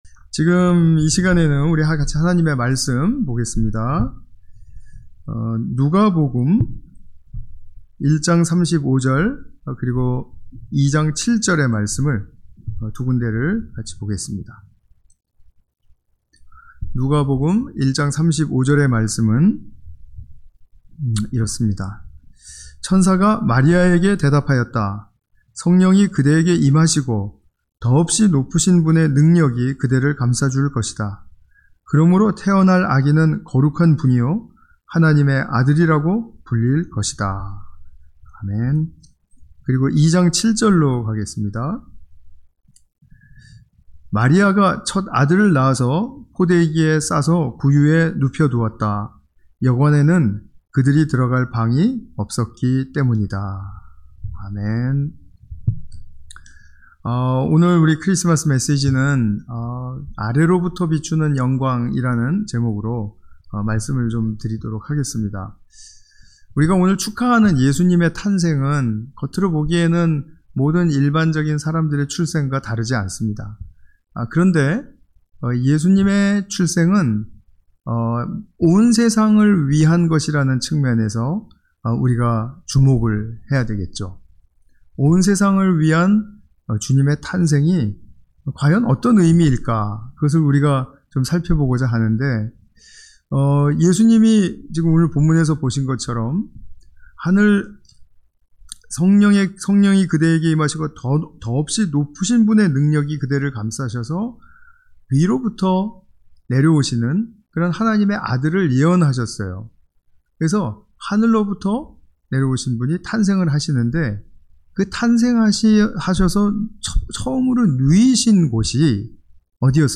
(성탄예배) 아래로부터 비추는 영광